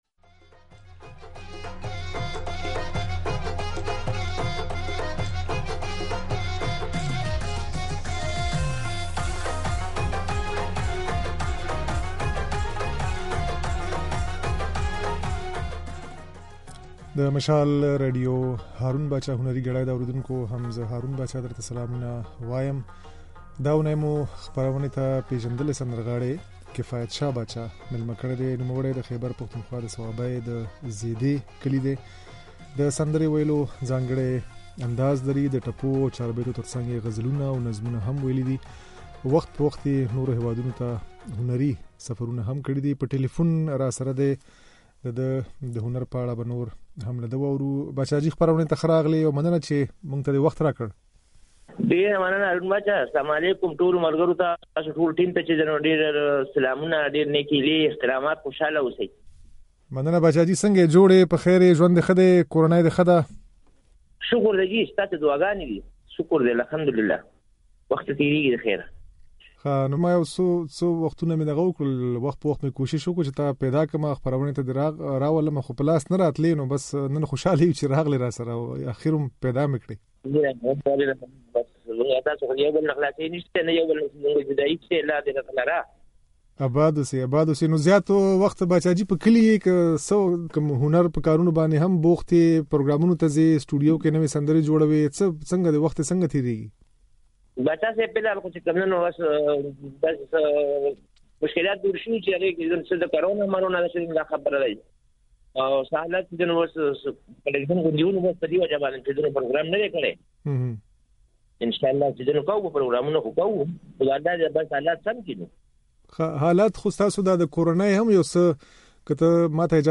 پېژندلی ولسي سندرغاړی